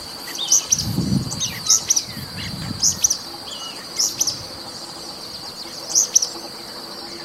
Uí-pi (Synallaxis albescens)
Nome em Inglês: Pale-breasted Spinetail
Condição: Selvagem
Certeza: Fotografado, Gravado Vocal